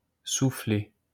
A soufflé (French pronunciation: [sufle]